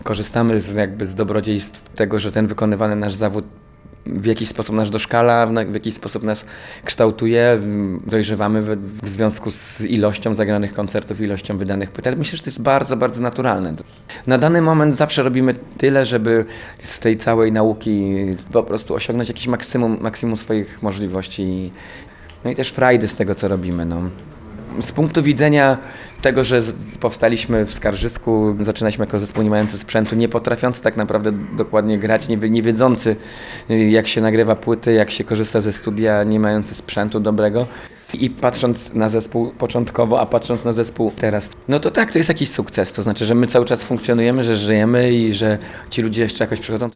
– Wartością jest dla nas to, że ludzie przychodzą na koncerty- powiedział Radiu 5 Jakub Kawalec, wokalista zespołu Happysad.